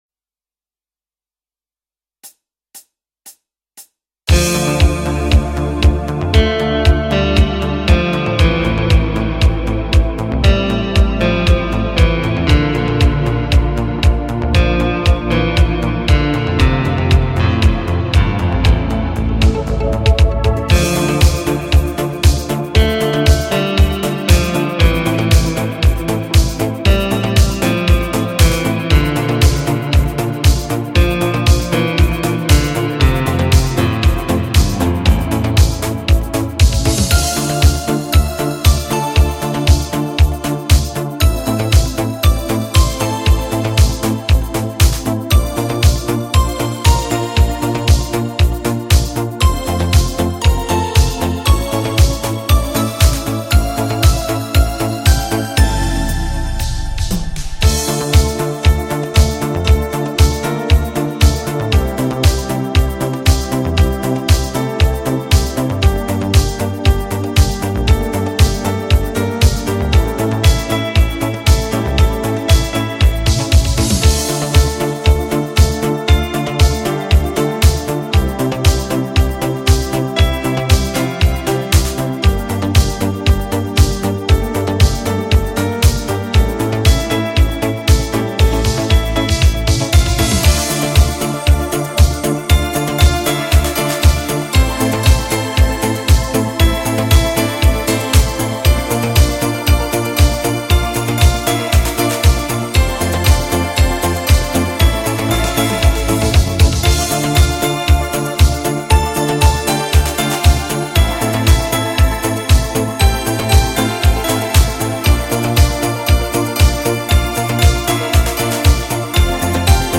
Такой классненький инструментал, на классную песню 80-х